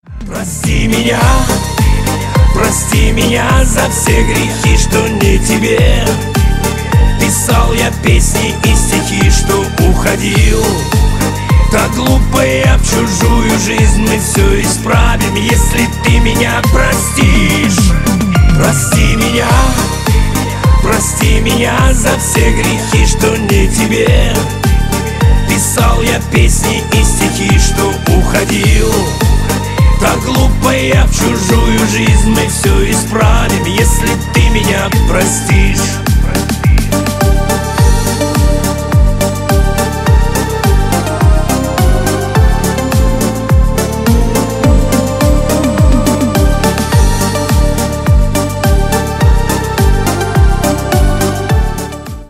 Шансон новинка 2024 на звонок
• Качество: Хорошее
• Песня: Рингтон, нарезка